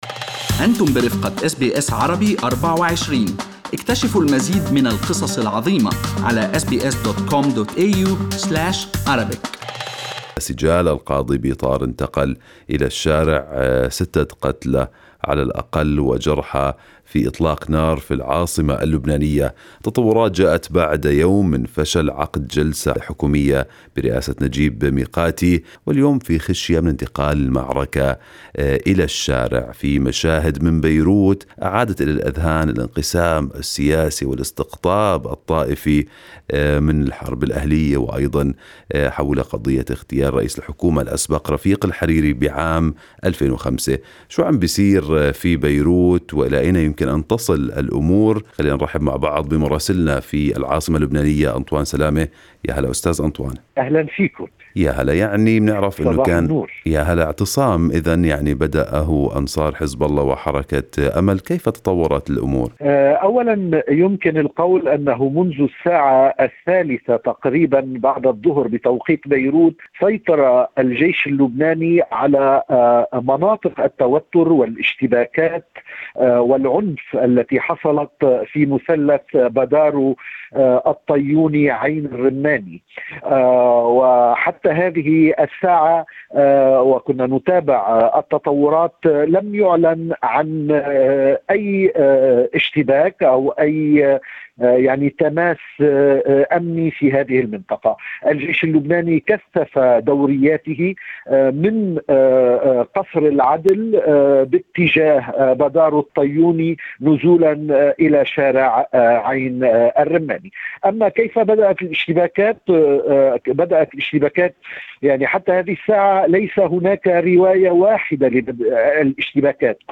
"كانوا مدججين بالسلاح": شاهد عيان يصف ما رأى من اشتباكات بيروت التي أوقعت ستة قتلى